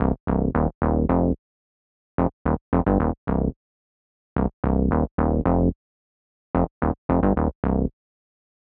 30 Bass PT1.wav